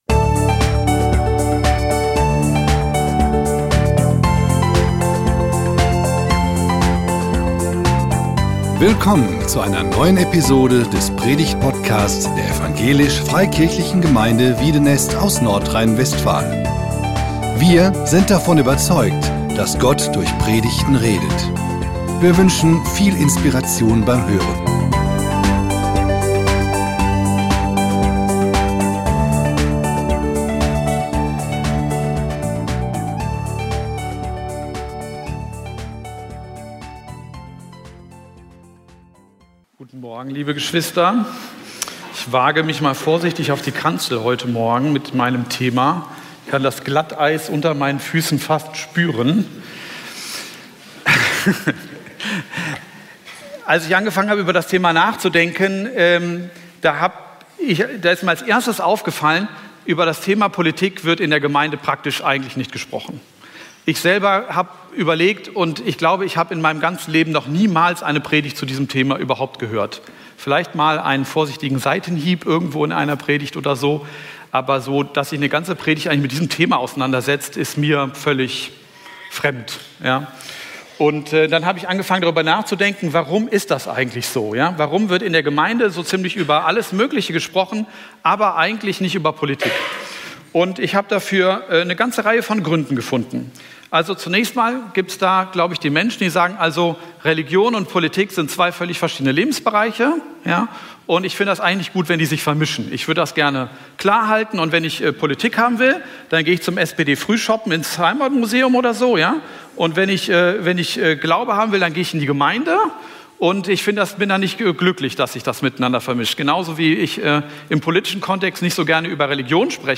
Christ und Politik - Predigt